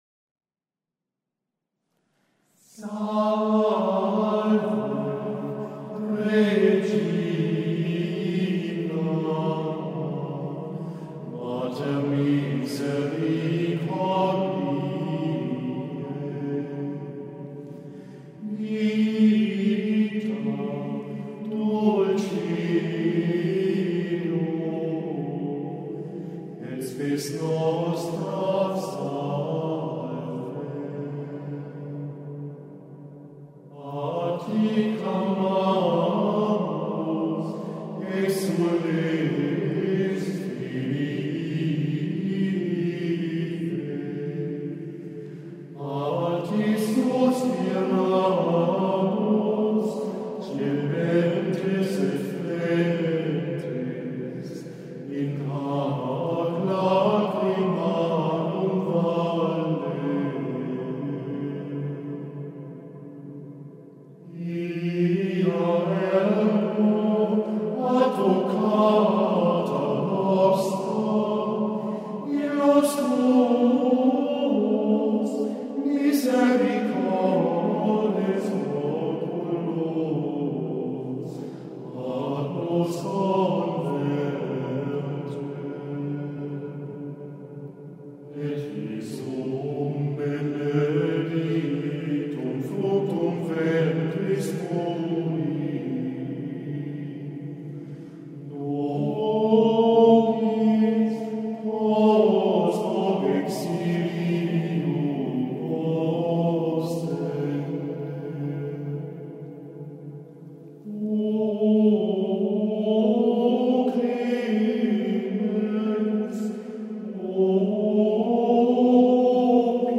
Klangbeispiel dieses Marienhymnus, gesungen von der Schola Cantorum Saliensis zum Download.